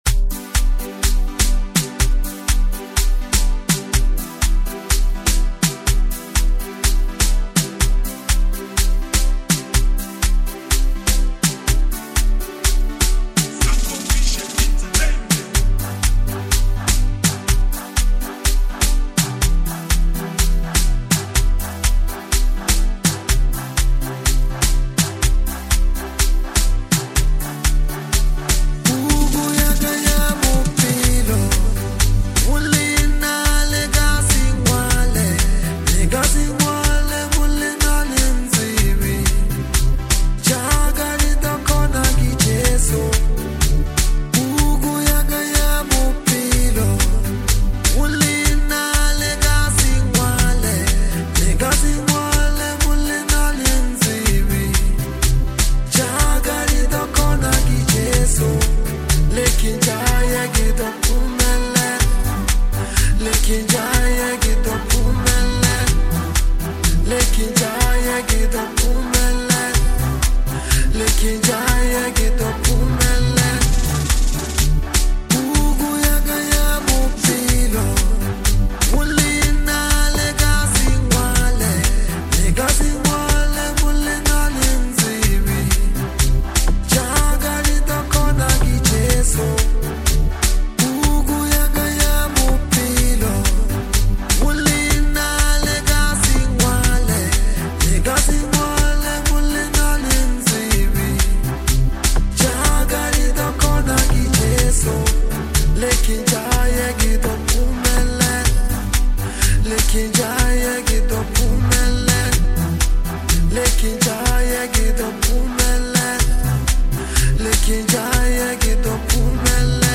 a reflective,musical autobiography